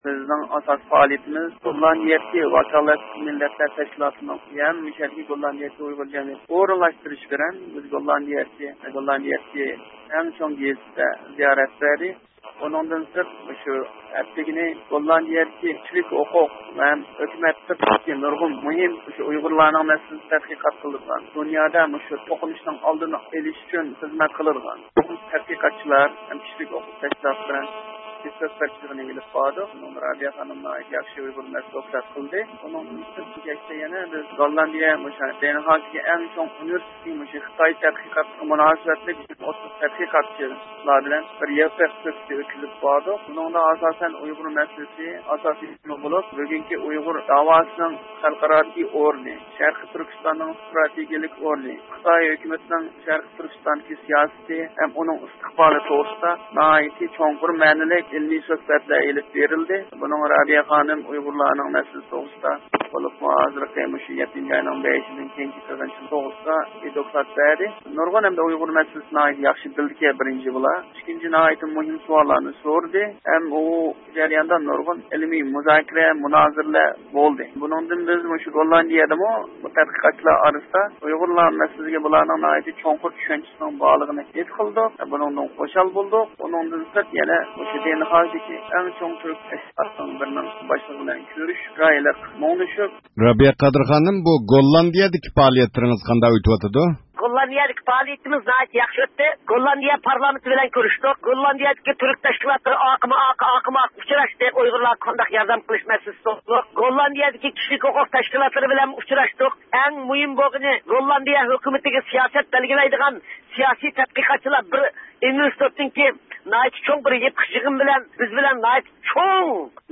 تېلېفوندا زىيارەت قىلدۇق